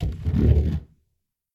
0027_从座位上站起.ogg